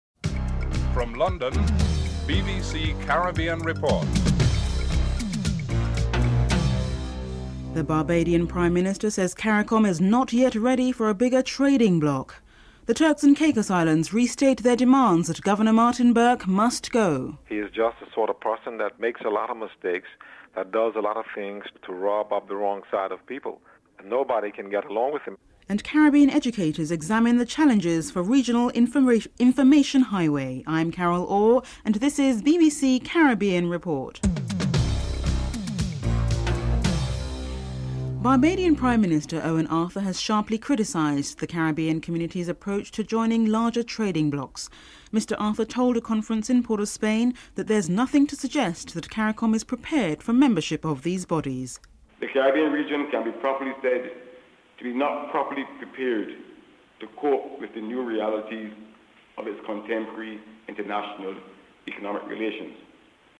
1. Headlines (00:00-00:36)
2. The Barbados Prime Minister says that Caricom is not yet ready for a bigger trading bloc. Prime Minister Owen Arthur, Mexican Ambassador to Port of Spain Isabel de Ortega and Jamaica's High Commissioner to Port of Spain Cherrie Orr are interviewed.
Education Minister for St. Lucia Louis George is interviewed (09:59-11:55)